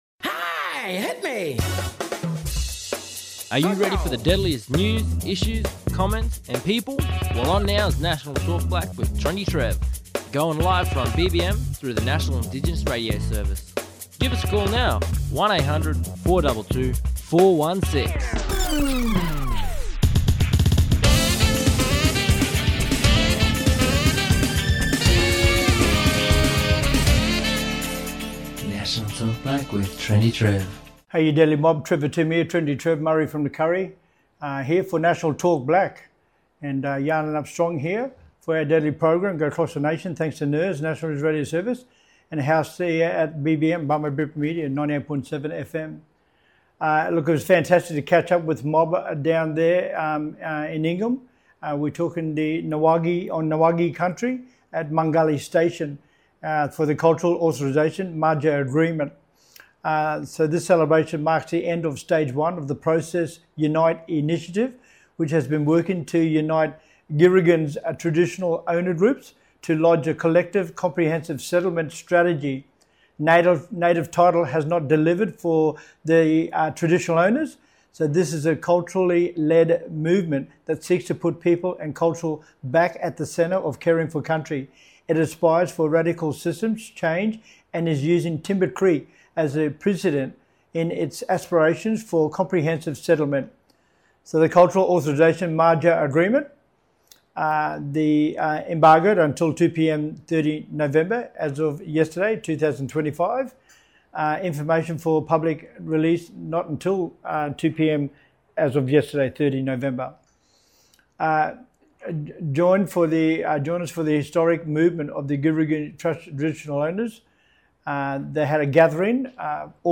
On todays National Talk Black via NIRS – National Indigenous Radio Service we have:
Special Guests from Process Unite Cultural Authorisation and Maja Agreement. Process Unite formalised our bid for a comprehensive settlement on behalf of all participating Traditional Owner groups on November 28 to 30 this year.